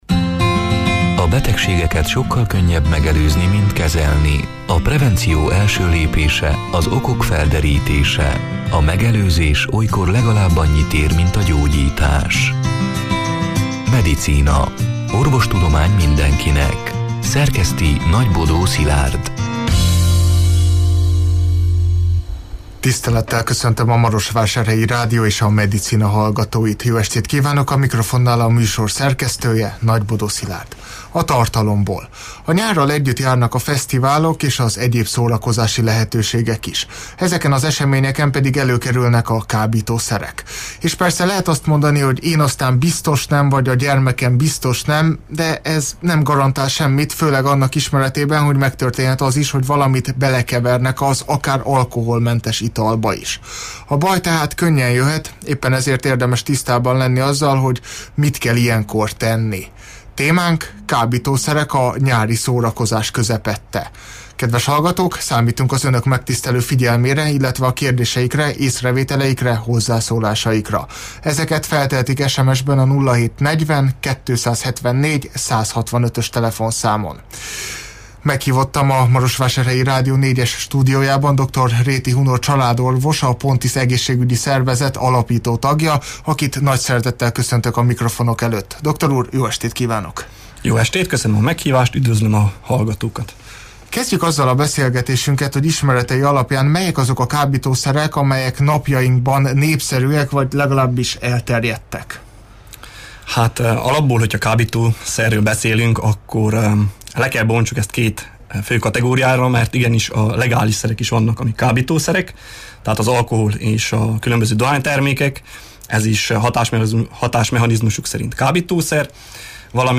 A Marosvásárhelyi Rádió Medicina (elhangzott: 2024. június 26-án, szerdán este nyolc órától élőben) c. műsorának hanganyaga: A nyárral együtt járnak a fesztiválok és az egyéb szórakozási lehetőségek is.